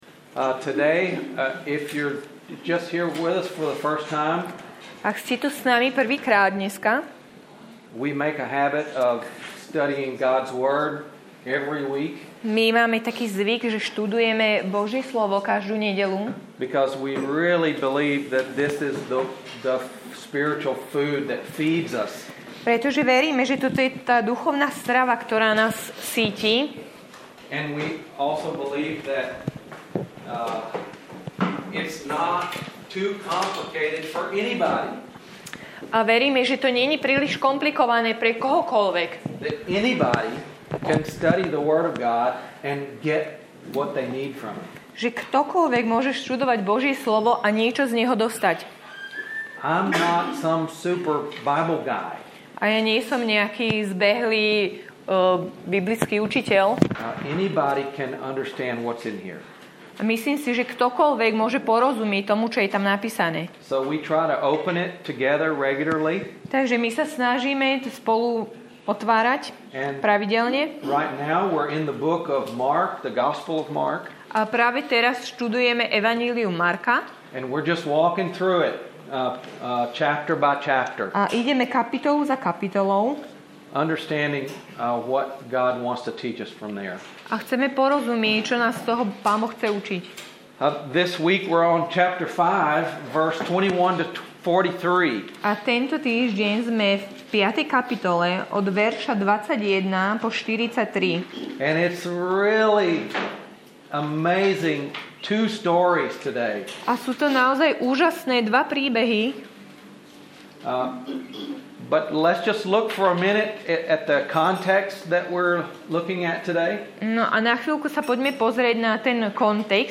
Nahrávka kázne Kresťanského centra Nový začiatok z 10. apríla 2011